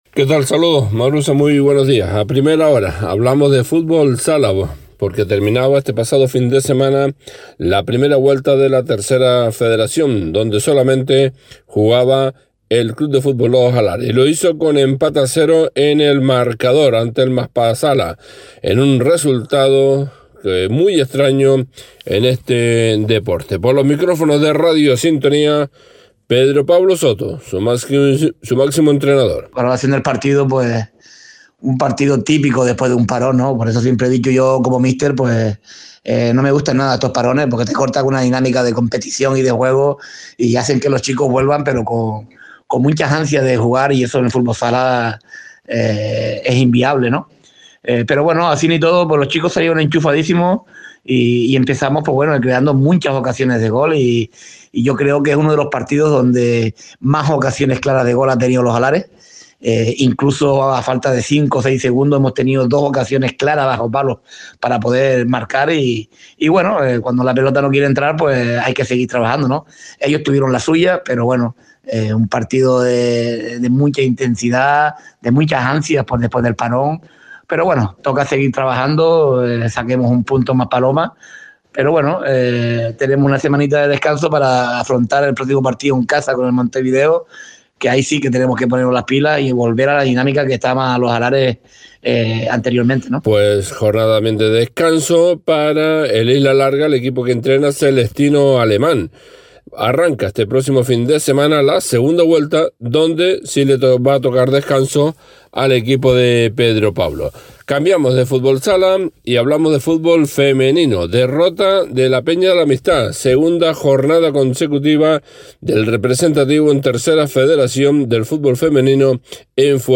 A Primera Hora, crónica deportiva